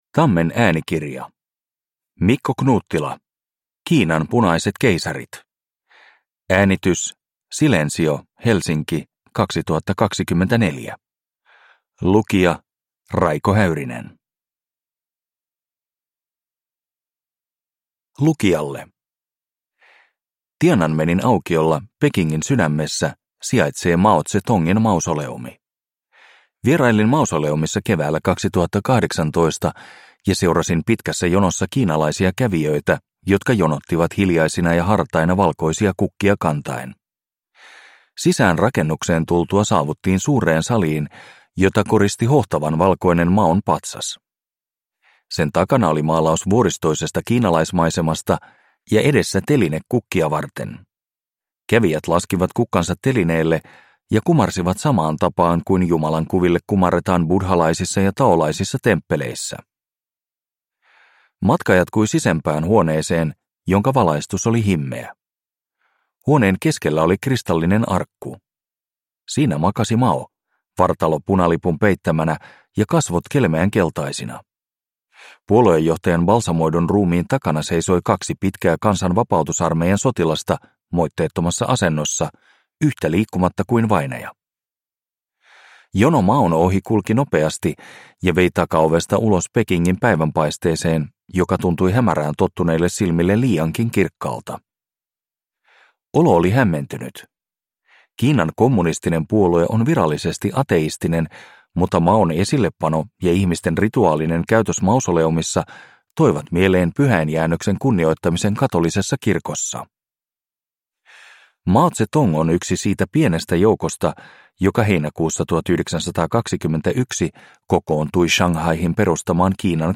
Kiinan punaiset keisarit – Ljudbok